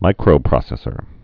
(mīkrō-prŏsĕs-ər)